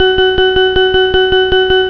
extra_life.wav